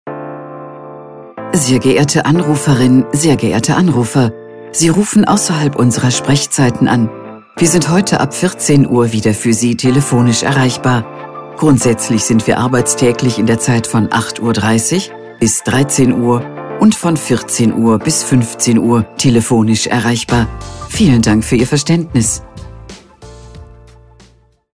Telefonansage Gericht
Telefonansage Amtsgericht Essen